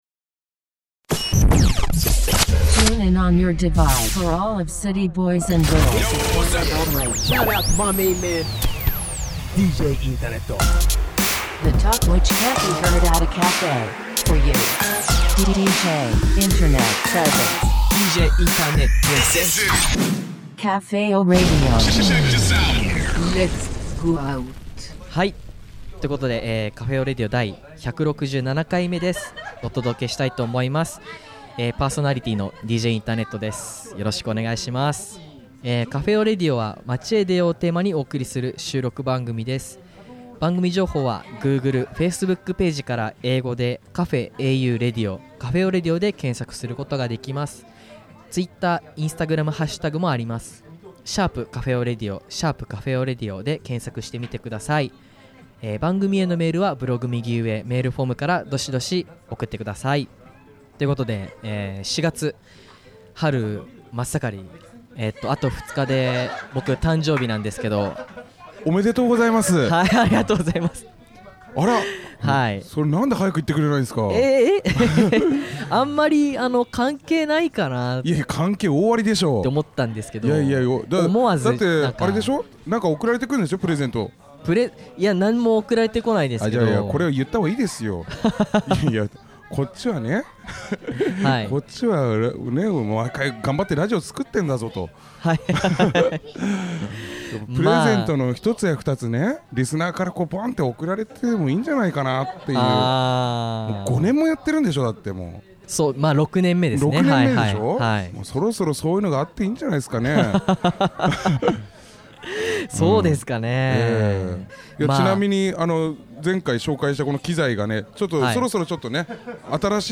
YOL Cafe Froschから お届けしております！